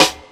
Snare (6).wav